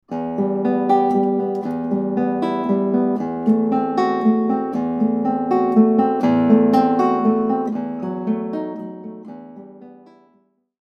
Accent Symbol | The fourth note in each measure is accented (accent in red).